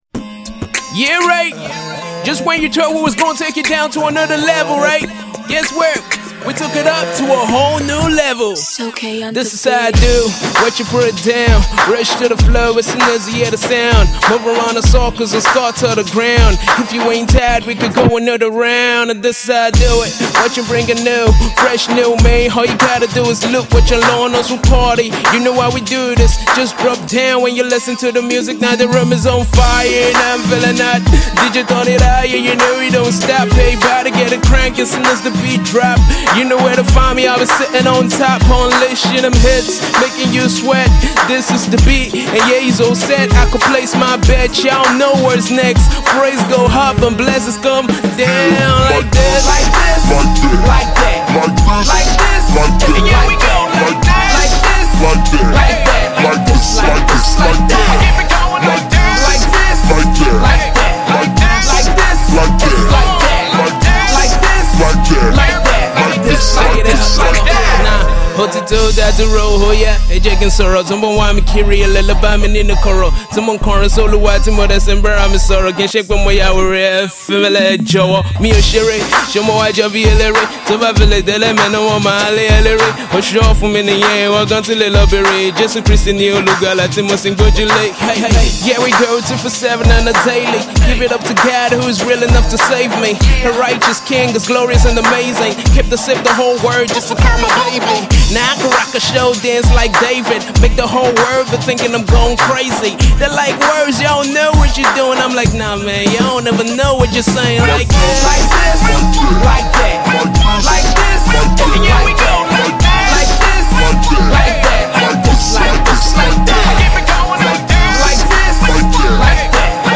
This is a classic hip hop fusion